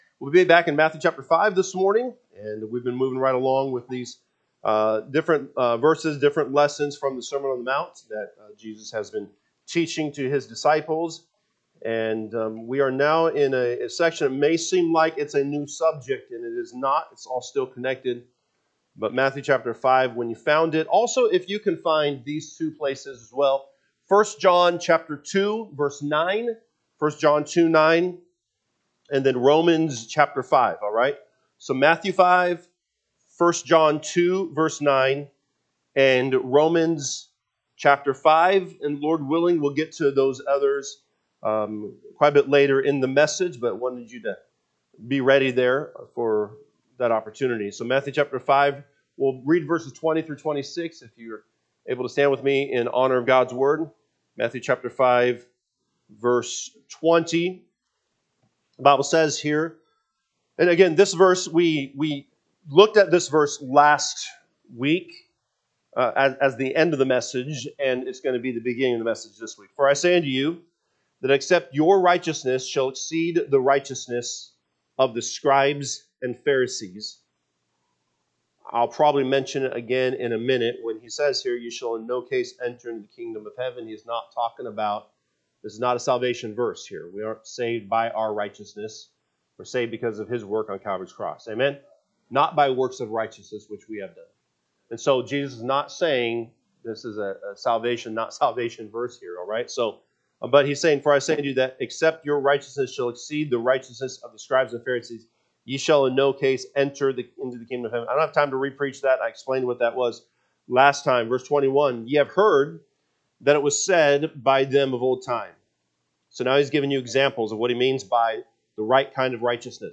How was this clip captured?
April 26, 2026 am Service Matthew 5:20-26 (KJB) 20 For I say unto you, That except your righteousness shall exceed the righteousness of the scribes and Pharisees, ye shall in no case enter int…